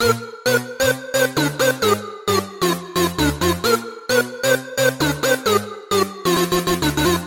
旧学校的进步CMaj
描述：老式合成器在CMaj中的进展。 电子电气
标签： 132 bpm Electronic Loops Synth Loops 1.22 MB wav Key : C
声道立体声